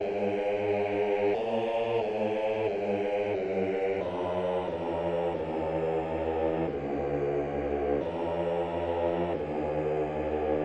黑暗合唱团
描述：用fl studio 12制作的方舟合唱团
Tag: 90 bpm Hip Hop Loops Choir Loops 1.79 MB wav Key : Unknown